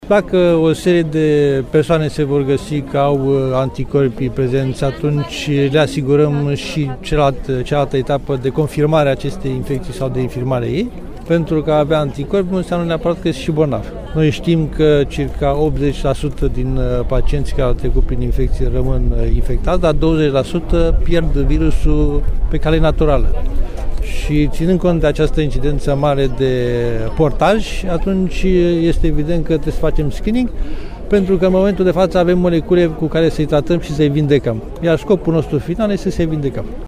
Managerul Institutului Național de Boli Infecțioase Prof. Dr. Adrian Streinu Cercel spune că testările vor continua pentru persoanele unde există suspiciuni ale bolii.